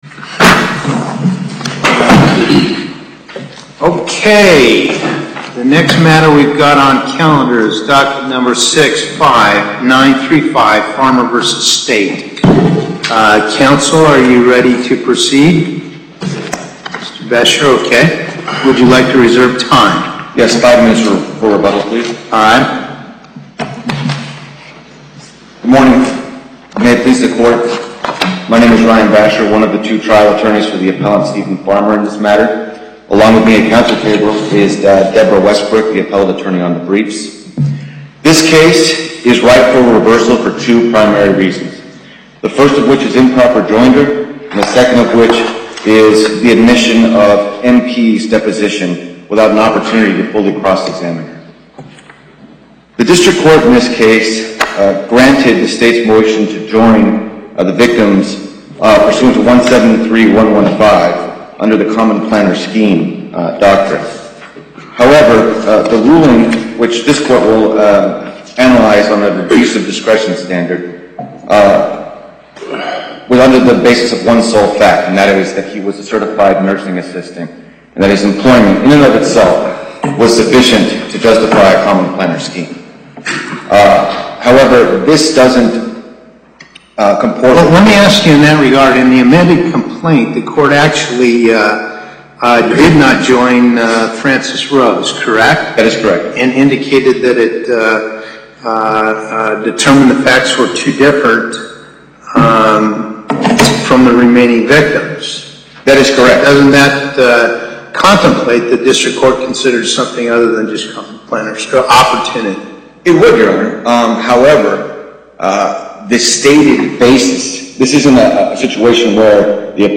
Location: Las Vegas Before the En Banc Chief Justice Parraguirre, Presiding